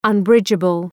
Προφορά
{ʌn’brıdʒəbəl}